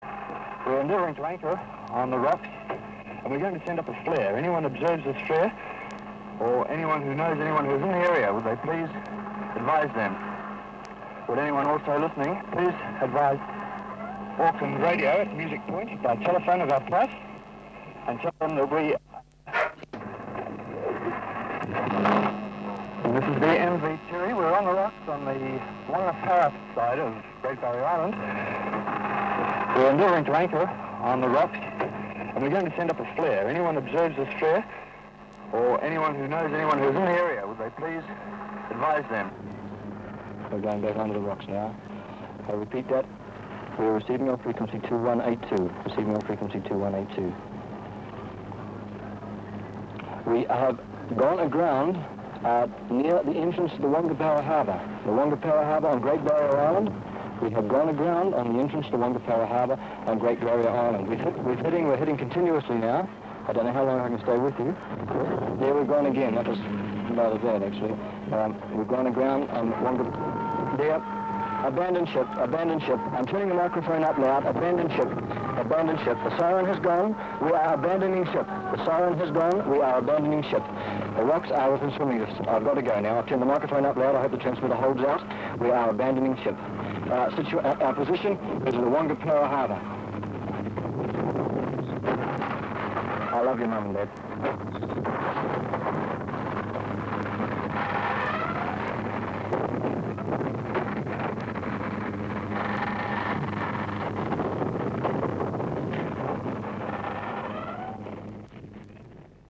As these announcements were being made listeners could clearly hear in the background the noise of the Tiri as she crashed against the rocks.
After playing a couple of Radio Hauraki jingles the station's transmitter went silent.
R Hauraki Tiri running aground.mp3